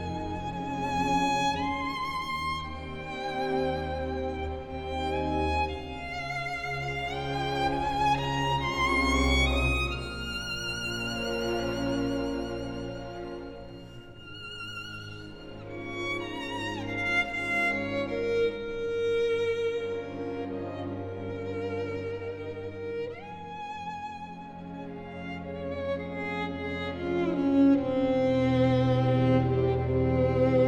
violoniste
musique de film